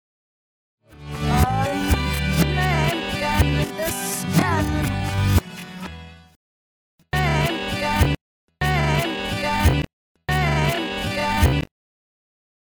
country rock
Tipo di backmasking Bifronte